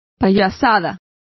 Complete with pronunciation of the translation of charades.